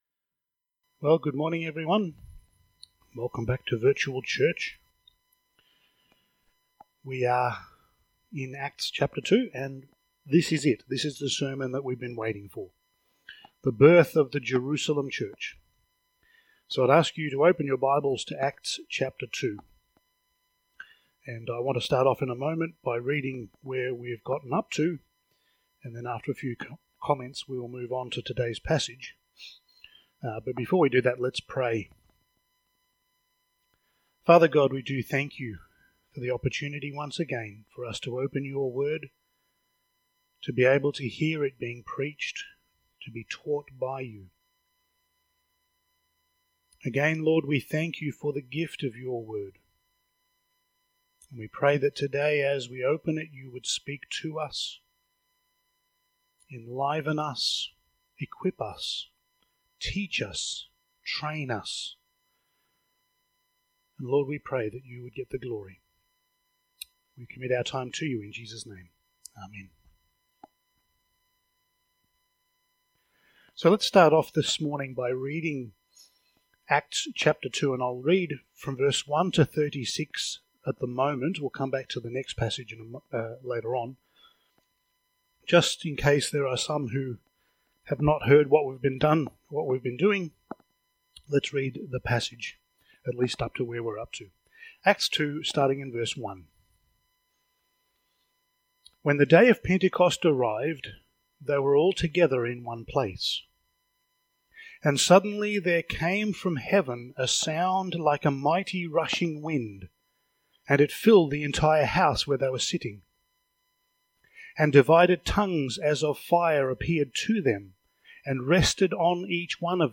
Passage: Acts 2:37-47 Service Type: Sunday Morning